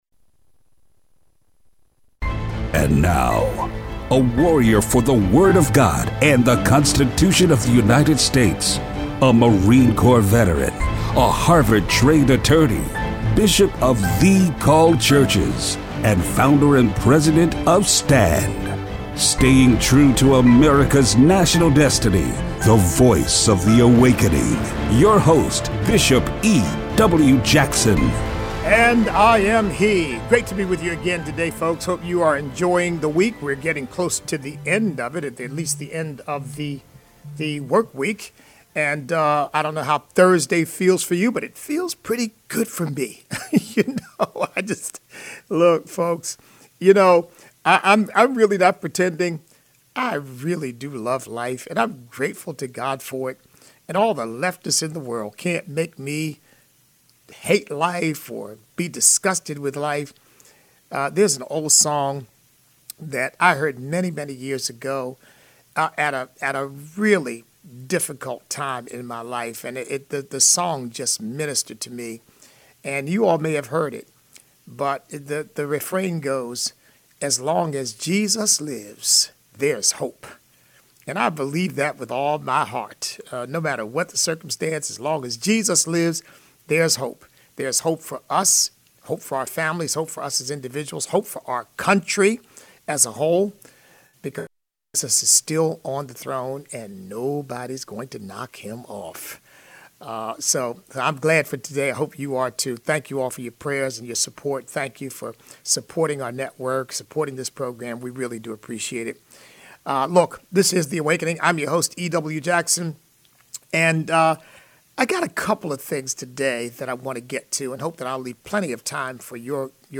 Listener call-in.